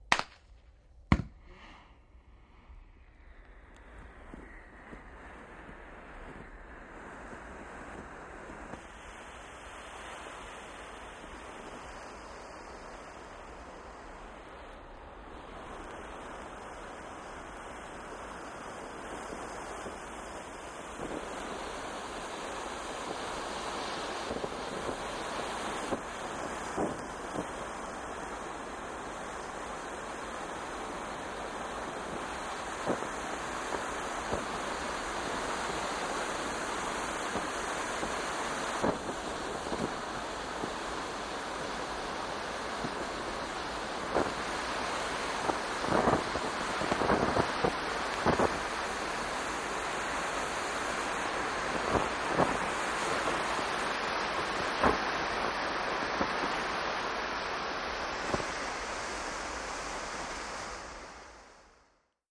Smoke Grenade: Pop, Ignite, And Smoke Close-up. Stereo